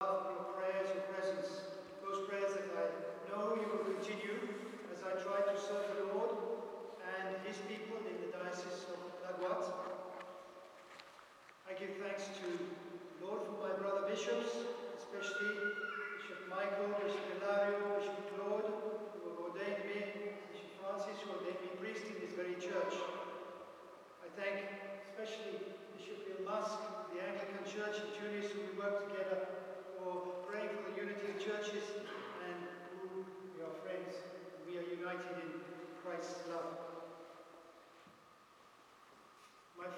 ORDINATION EPISCOPALE de Mgr John MacWilliam
Monastère bénédictin de Worth Abbey
Remerciements de Mgr John MacWilliam (Extraits)